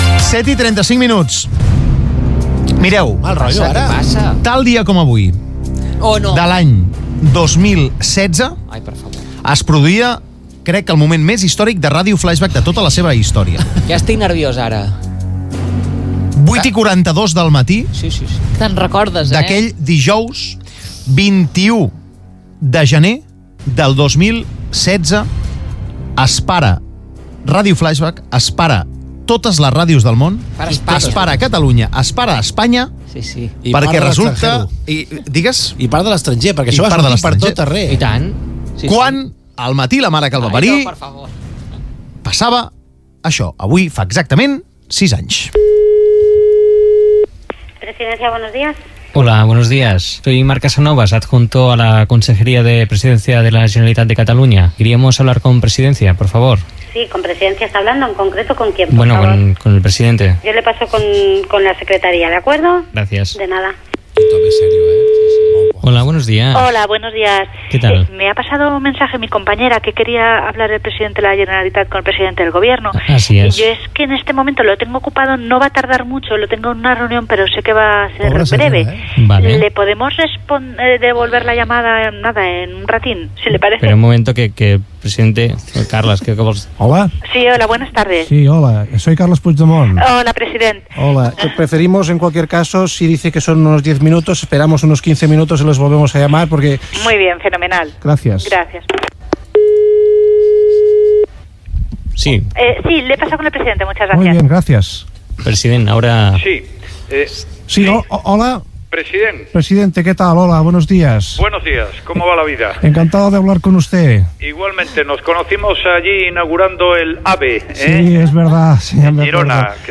Reproducció de l'enregistrament i explicació de l'equip del programa, al canal de Twitch de Flaixbac (flaixbacoficial), de com es va fer.
Programa distribuït en directe a Twitch, amb una explicació feta només a Twitch quan en FM sortia una cançó i publicitat.